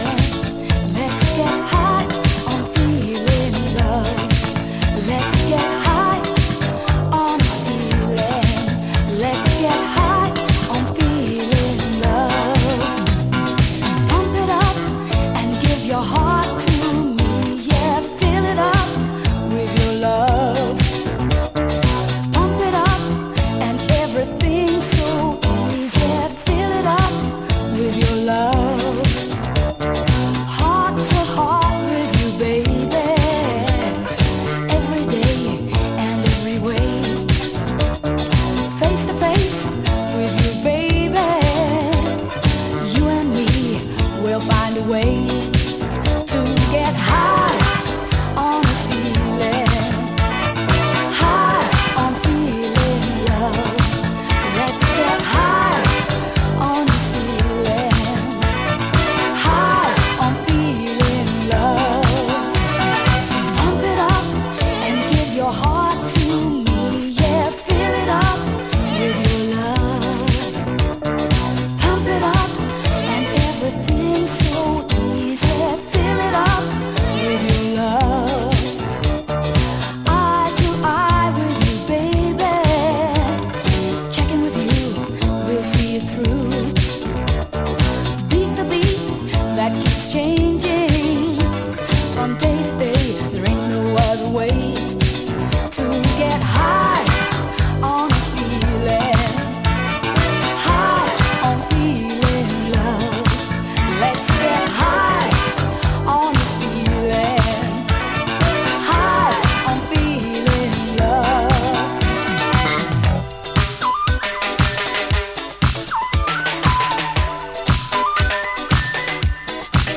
UKジャズファンク